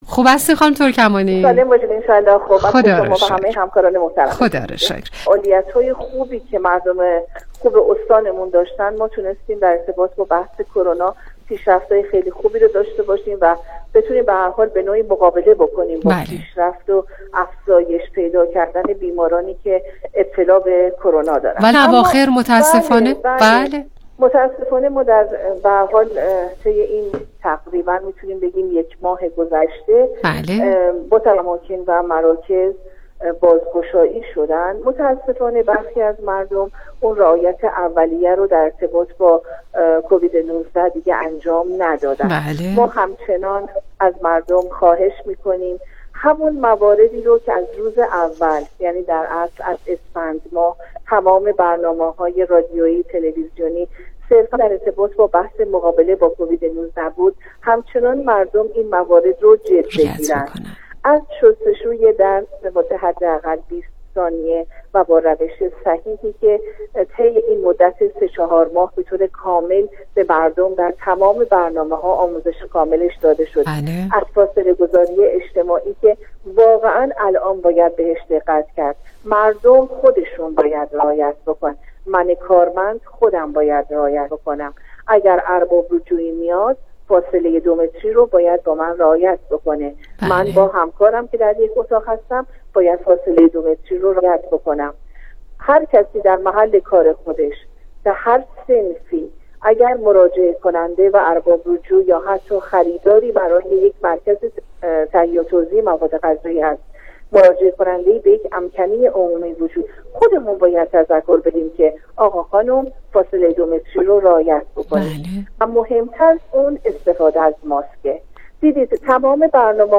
برنامه رادیویی